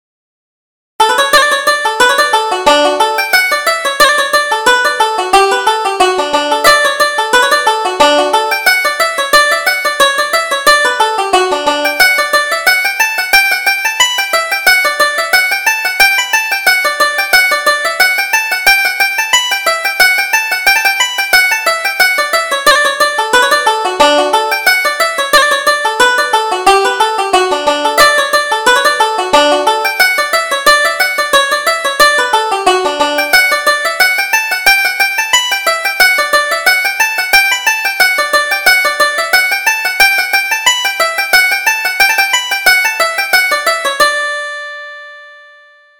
Reel: The Wexford Lasses